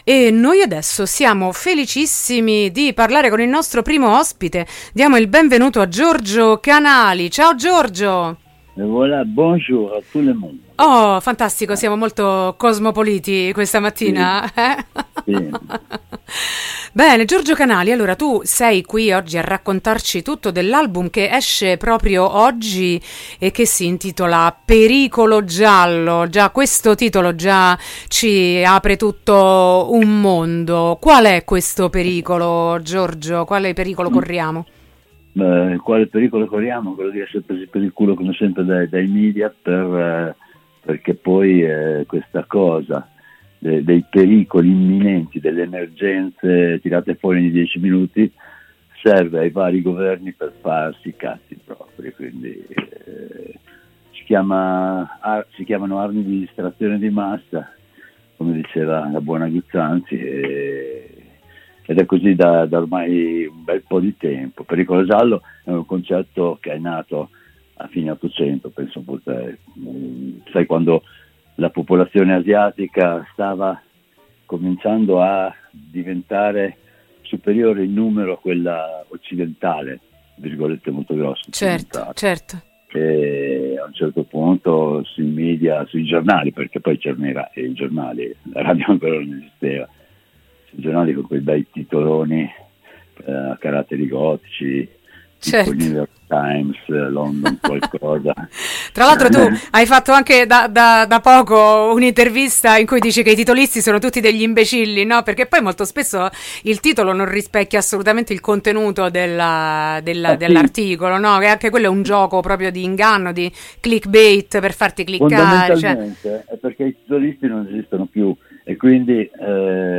“Pericolo giallo”: intervista a Giorgio Canali Rossofuoco | Radio Città Aperta